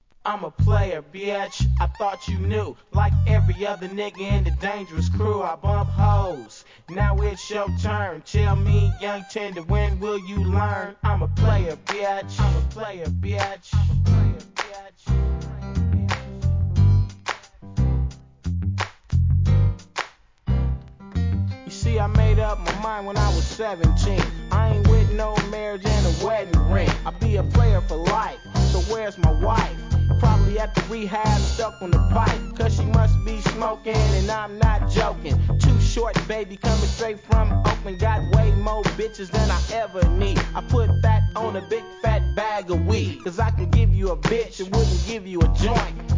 G-RAP/WEST COAST/SOUTH
使いのメロ〜FUNK!!